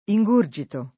ingurgitare v.; ingurgito [ i jg2 r J ito ]